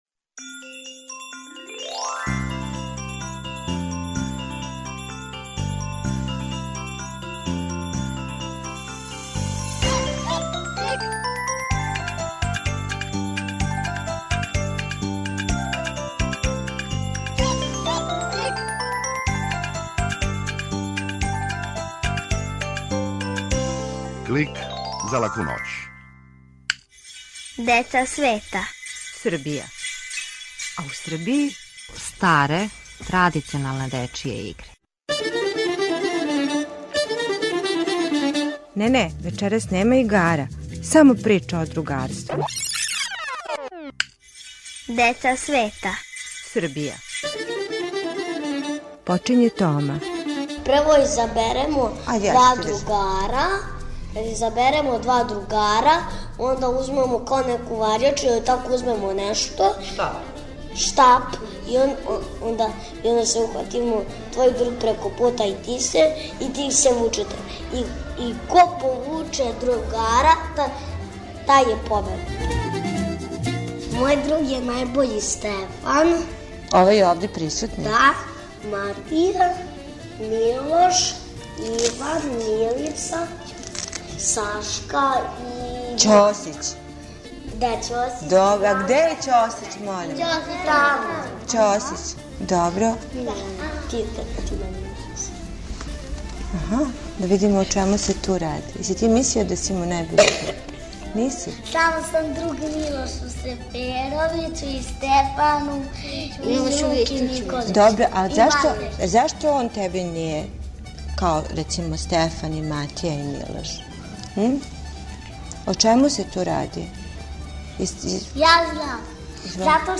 Клик је кратка емисија за децу, забавног и едукативног садржаја. Сваке седмице наши најмлађи могу чути причу о деци света, причу из шуме, музичку упознавалицу, митолошки лексикон и азбуку звука. Уколико желите да Клик снимите на CD или рачунар, једном недељно,на овој локацији можете пронаћи компилацију емисија из претходне недеље, које су одвојене кратким паузама.